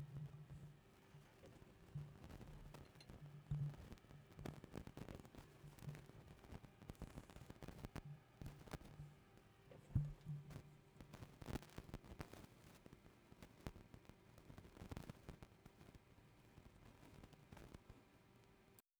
Behringer T-47 Треск